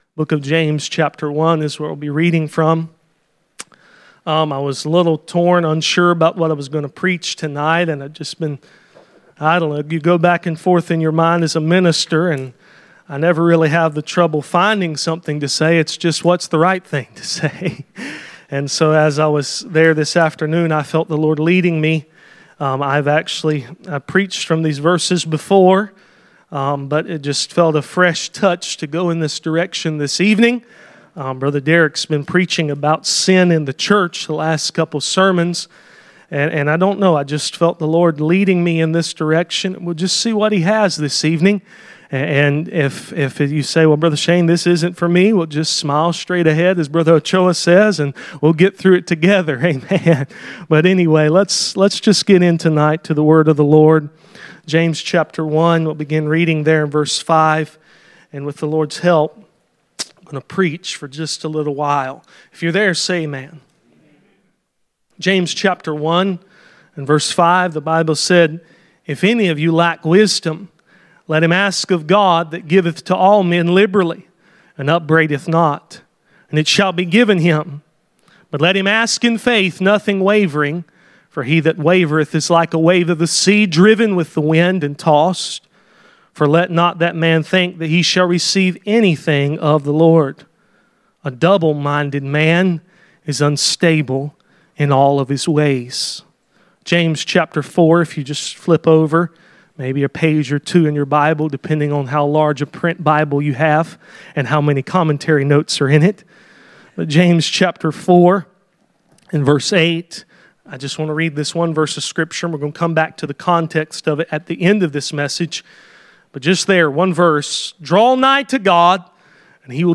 James 4:8 Service Type: Sunday Evening %todo_render% « Dealing with sin in the church